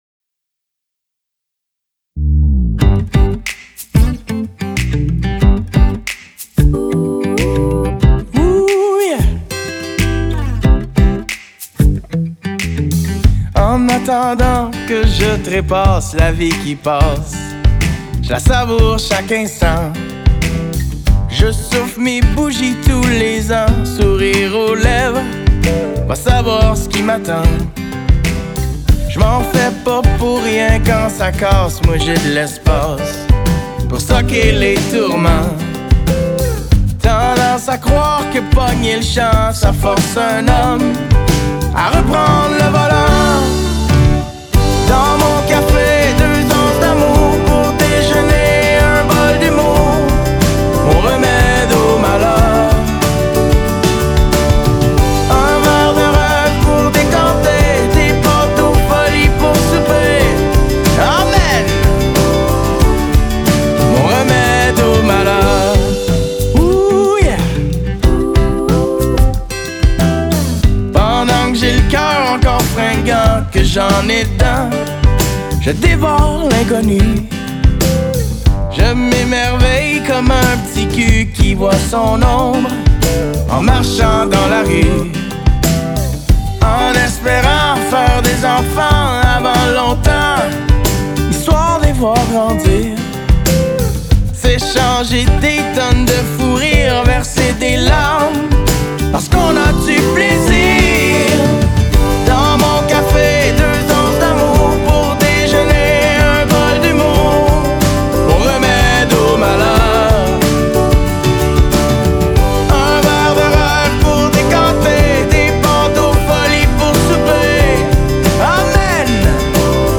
« flamenpop »